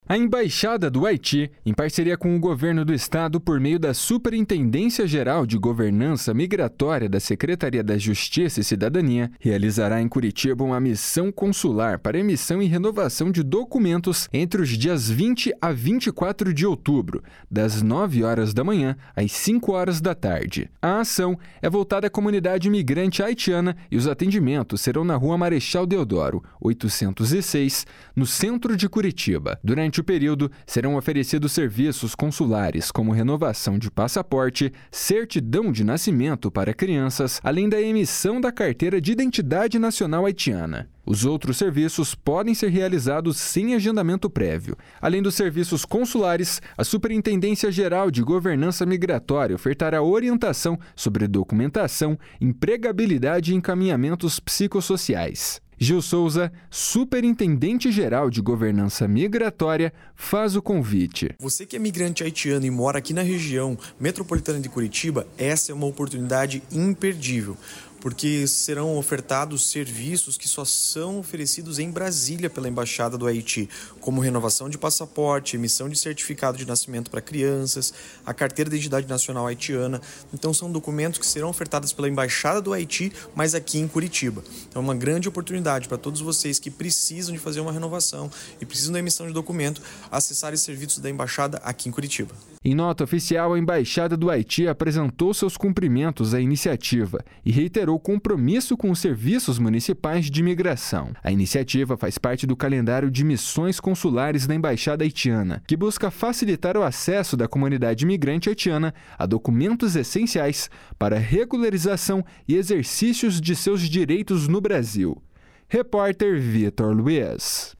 Gil Souza, Superintendente-Geral de Governança Migratória, faz o convite. // SONORA GIL SOUZA //